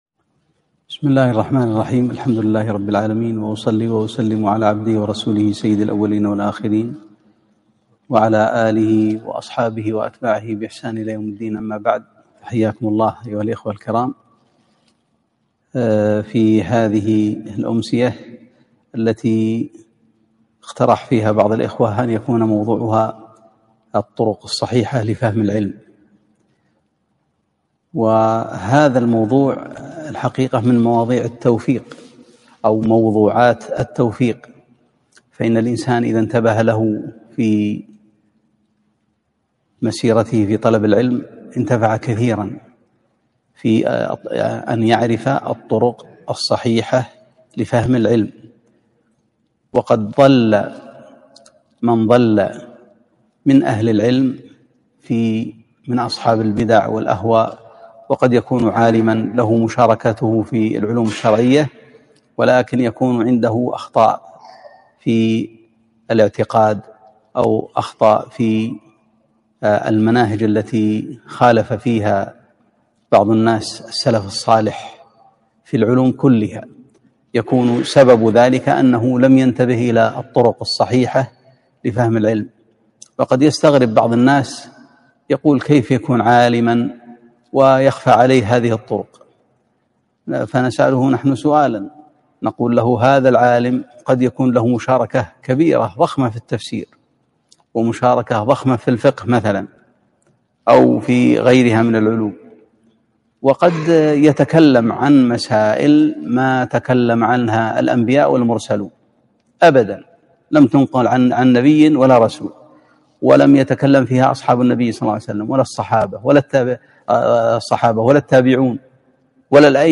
محاضرة - الطرق الصحيحة لفهم العلم